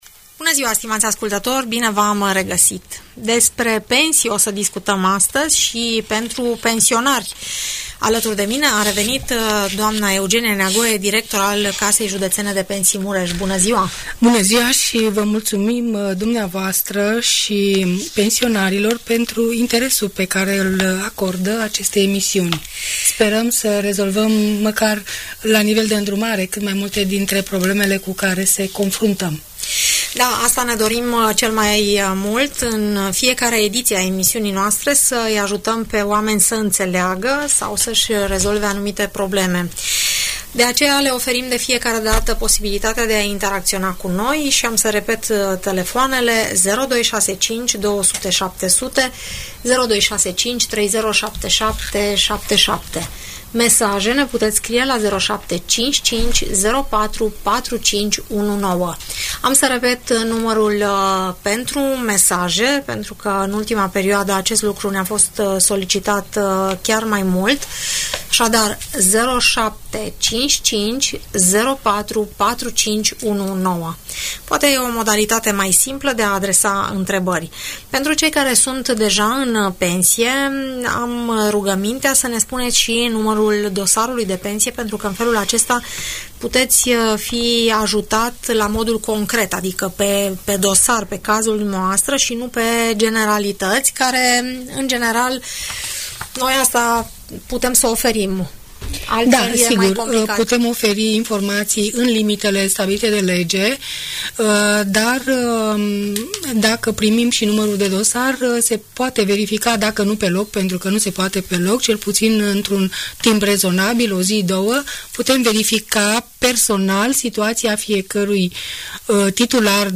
Audiență radio cu întrebări și răspunsuri pe tema pensiilor sociale de stat în emisiunea „Părerea ta” de la Radio Tg Mureș.